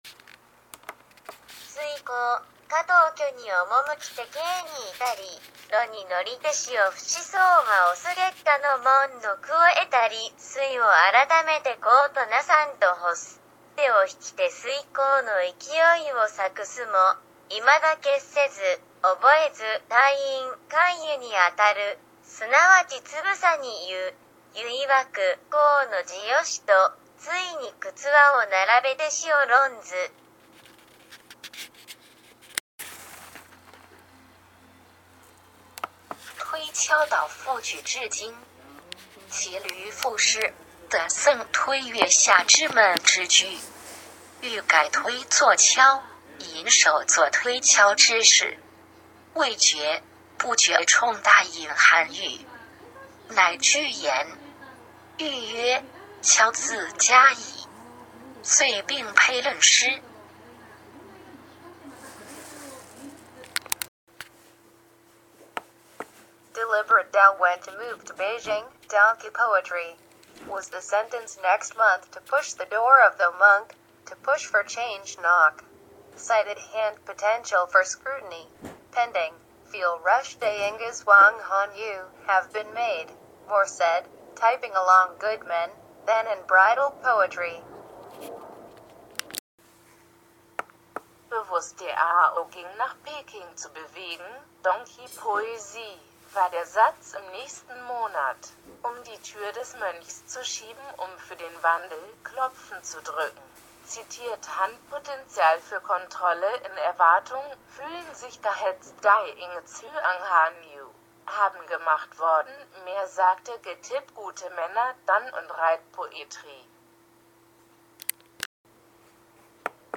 推敲読上各国語
推敲読上各国語.mp3